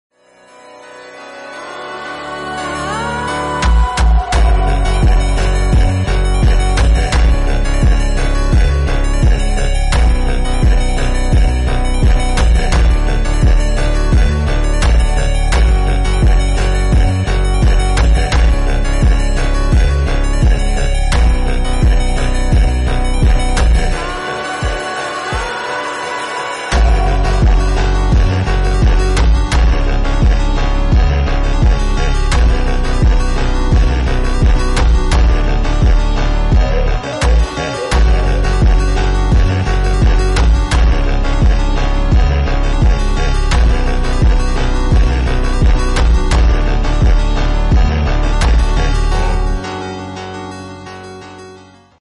Funk Edit
phonk remix